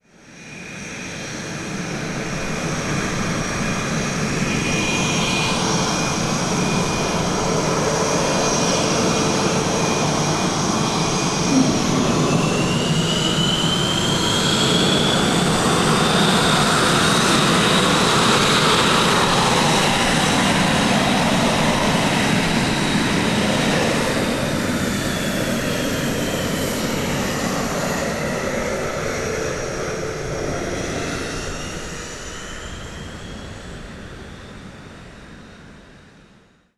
Rodadura de un avión F18
Sonidos: Transportes